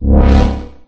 Fog1.ogg